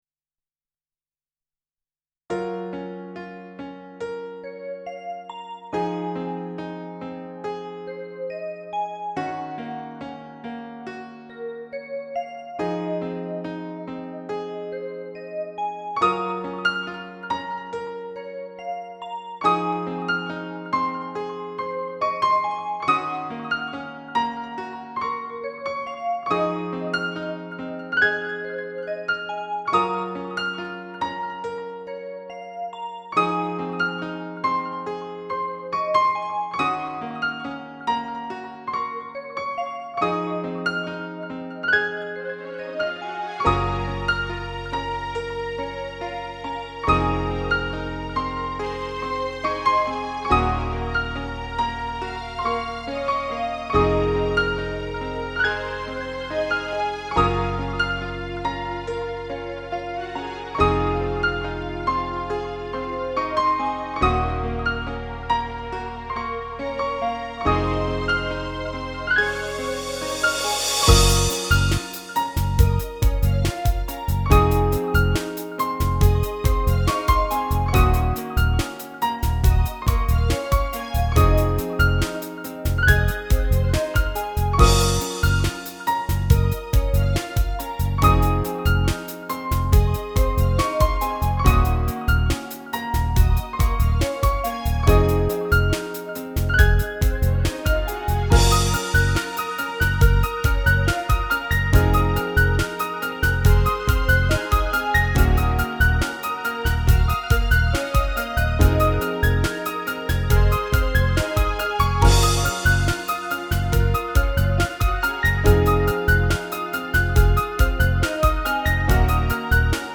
ピアノのディレイがえらいことになってます(^^;